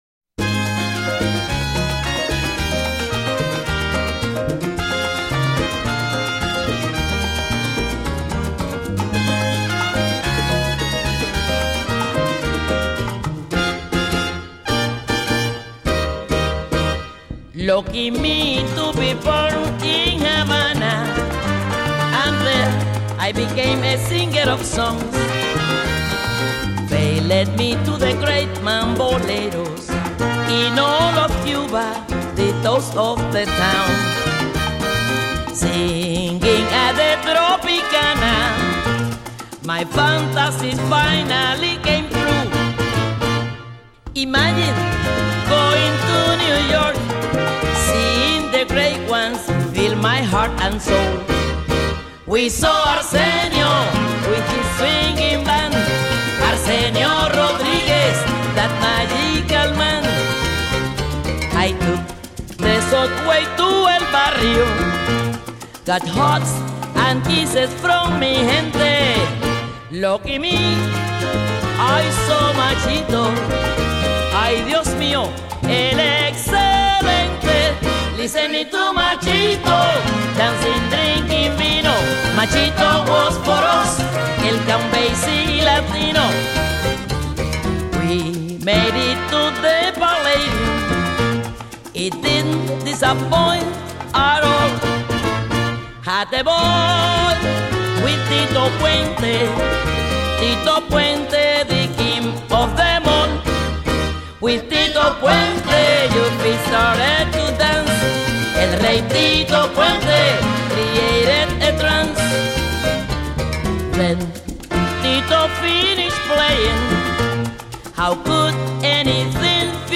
Cuban-American singer